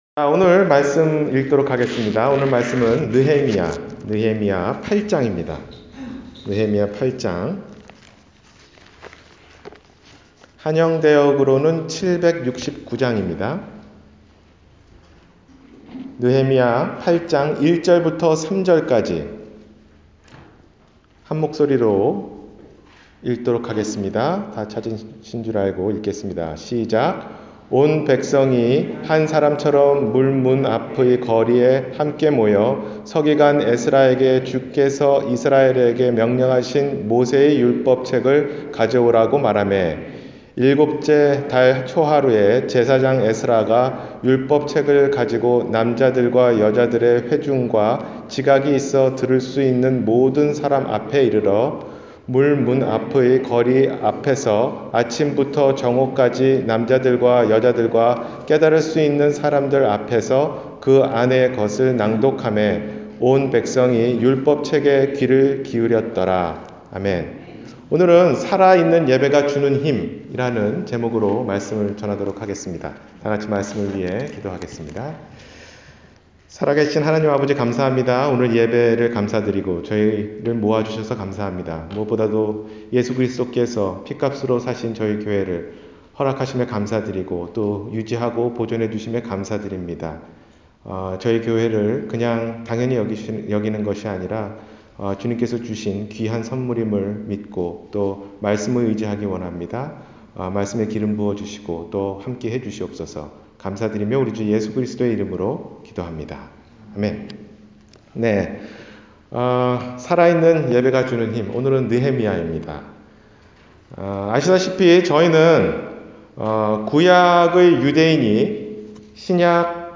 예배가 주는 힘 (느헤미야 8장)- 주일설교